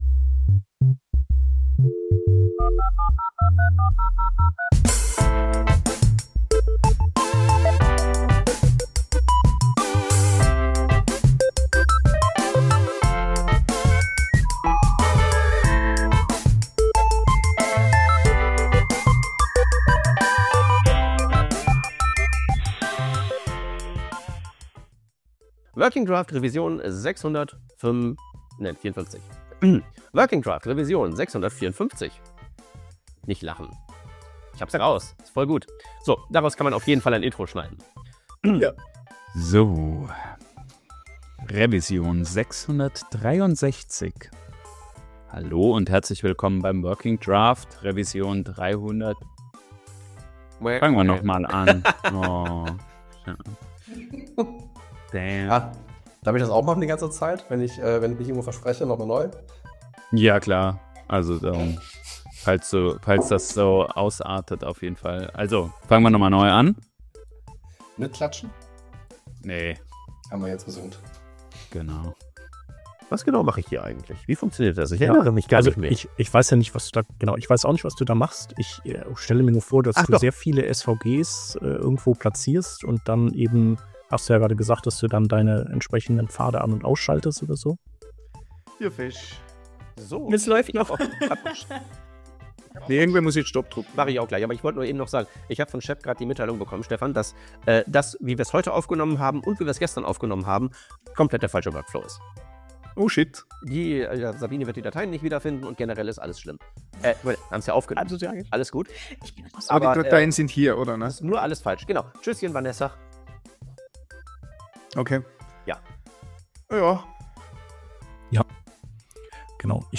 wd-outtakes-2025.mp3